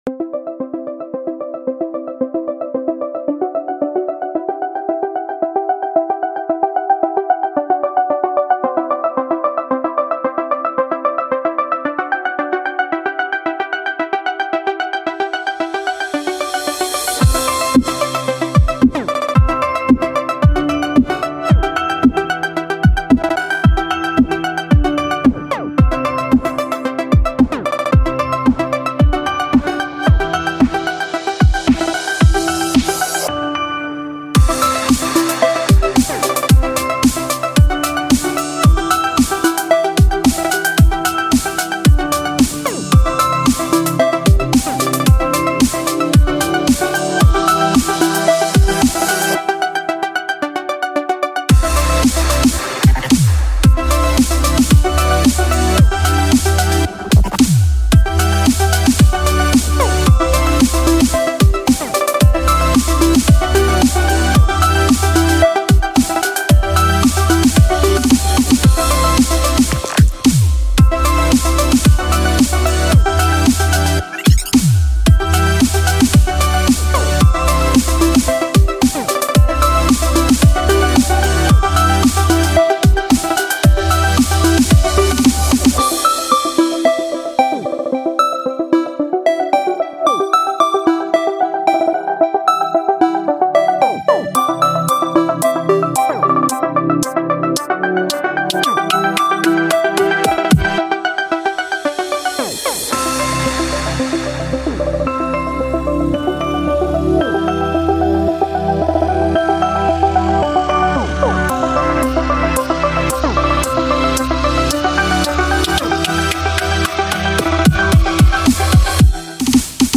Melodic Glitch Hop? Drumstep?
IT'S SO FULL OF EMOTION IT'S AMAZING.